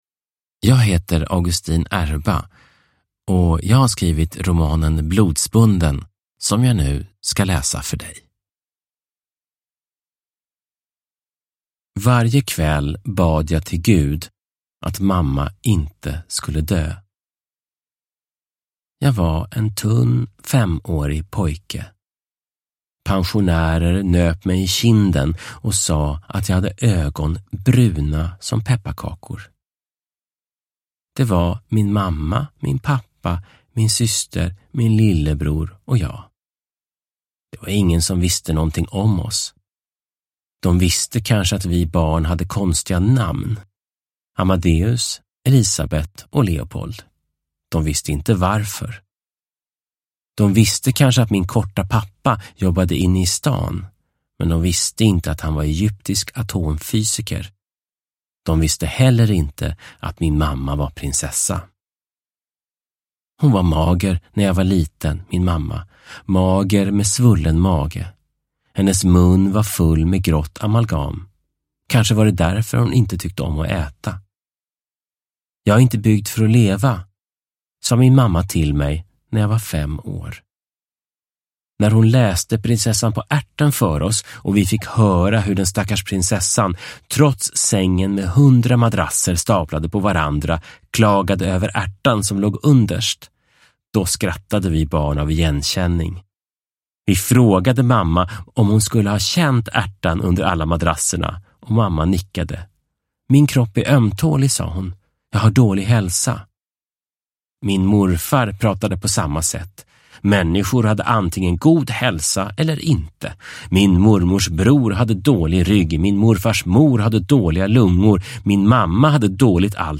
Blodsbunden – Ljudbok
Uppläsare: Augustin Erba